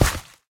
Sound / Minecraft / step / gravel4.ogg
gravel4.ogg